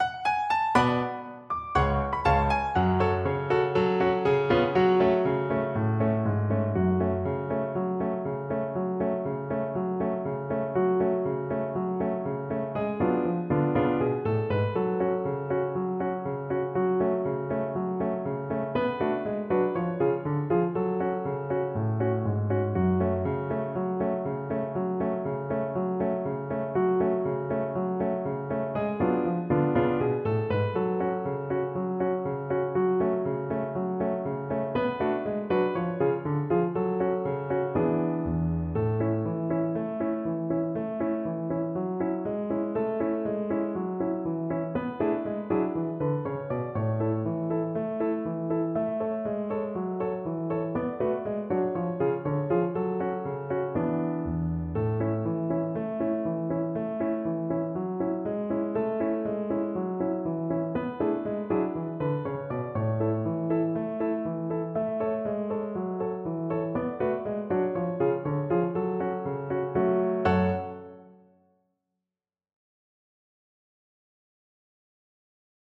Violin
4/4 (View more 4/4 Music)
F# minor (Sounding Pitch) (View more F# minor Music for Violin )
Allegro =c.120 (View more music marked Allegro)
Traditional (View more Traditional Violin Music)
world (View more world Violin Music)
Israeli